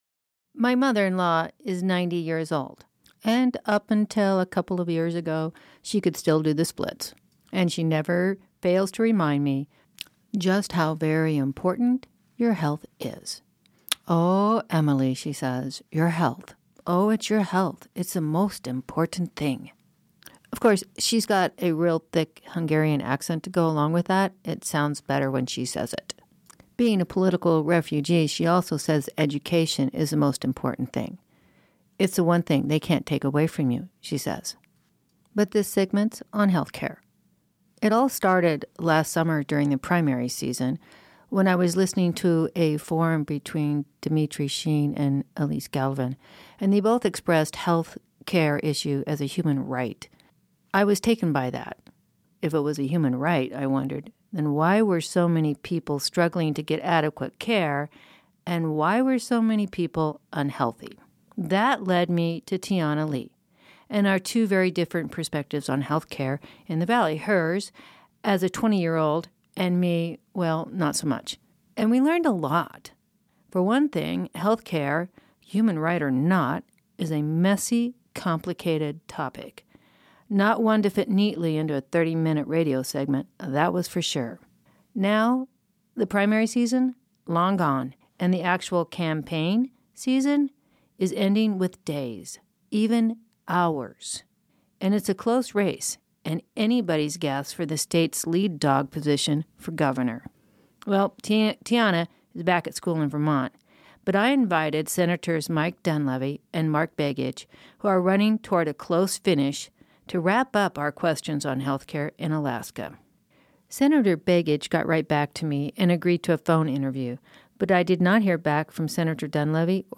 Senator Begich responded and was interviewed. The Dunleavy campaign did not respond, and an attempt was made to include their positions as well, gleaned from written statements and statements made during the campaign’s forums and debates.